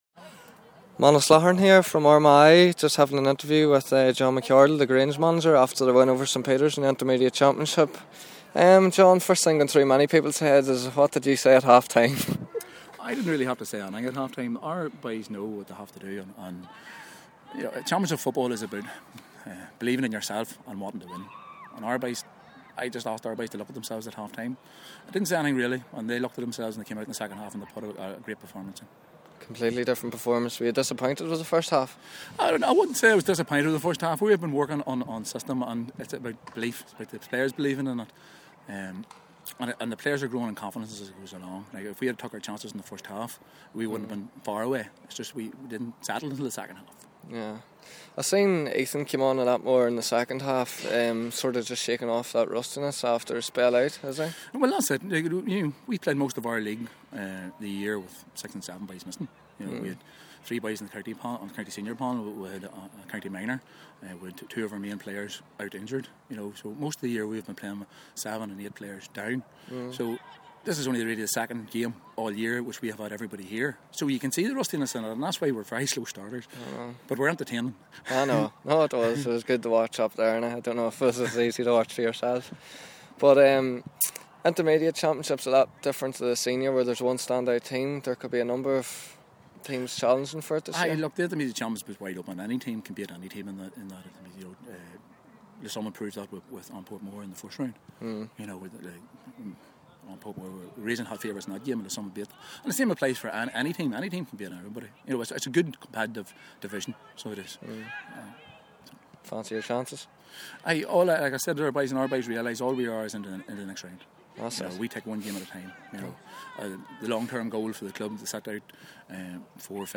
My interview